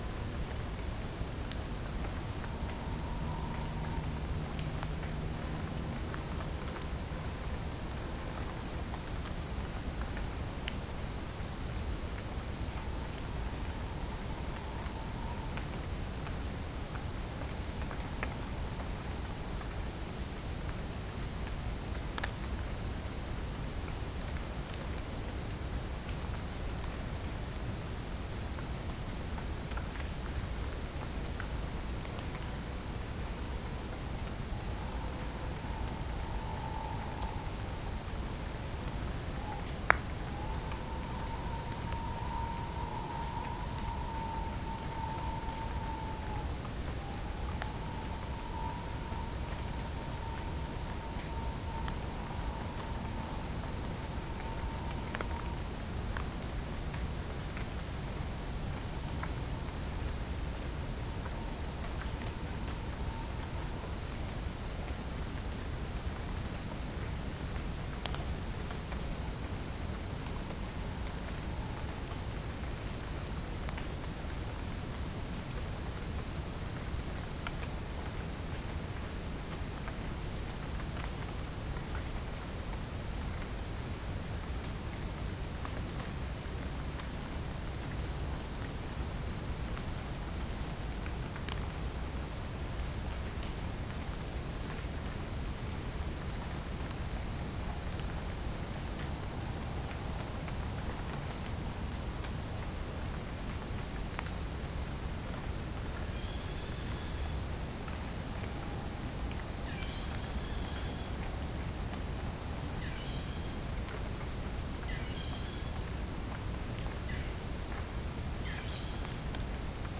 Geopelia striata
Pycnonotus goiavier
Halcyon smyrnensis
Orthotomus sericeus
Dicaeum trigonostigma